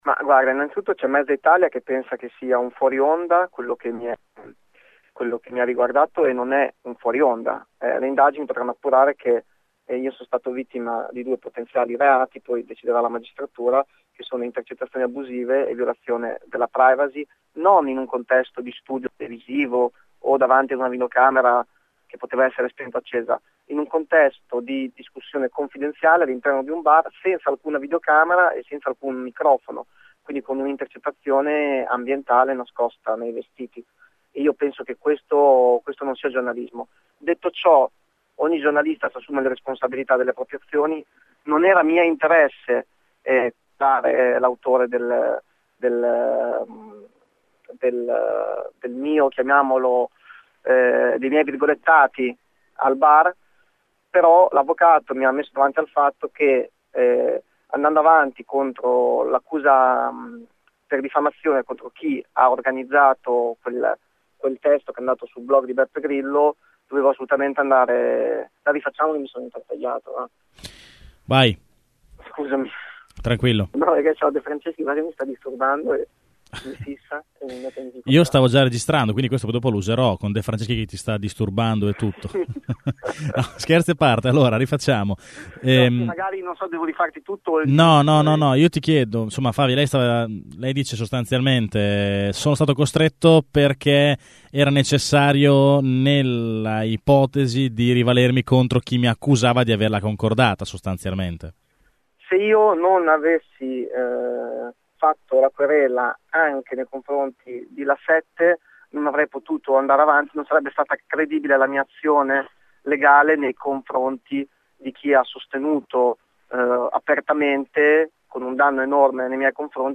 4 dic. – Le due querele che Giovanni Favia ha presentato ieri in Procura, e da cui sono scaturite due diverse indagini, sono strettamente legate: “Una non sarebbe stata possibile senza l’altra”. A dirlo è lo stesso consigliere regionale del Movimento 5 Stelle che ai nostri microfoni spiega ilperché ha scelto di rivolgersi alla Magistratura.